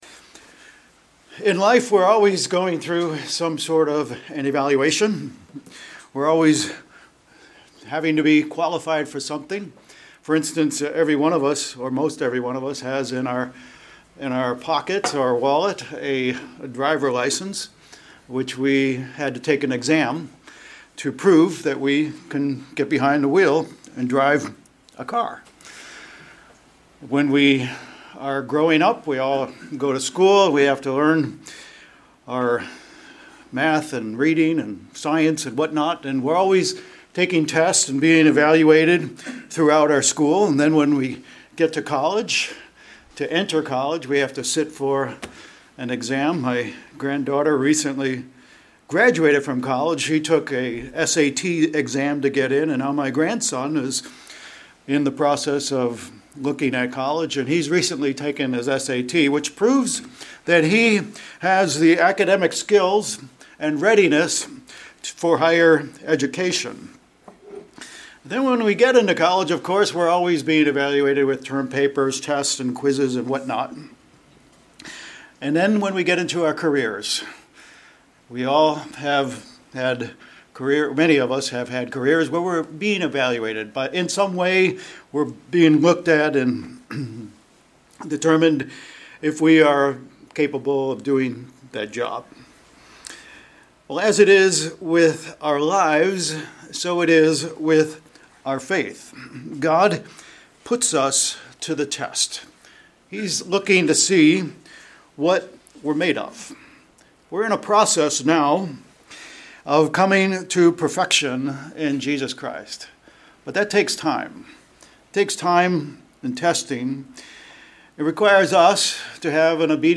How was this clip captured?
Given in Vero Beach, FL